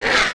快速拨开zth070521.wav
通用动作/01人物/02普通动作类/快速拨开zth070521.wav
• 声道 單聲道 (1ch)